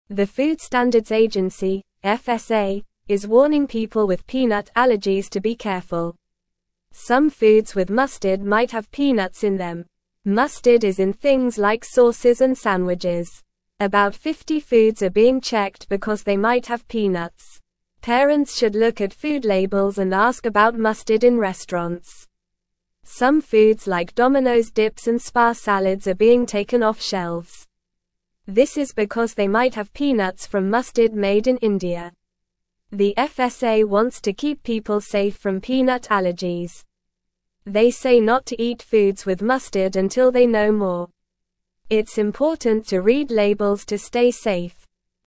Normal
English-Newsroom-Beginner-NORMAL-Reading-Warning-for-Peanut-Allergies-Check-Foods-with-Mustard.mp3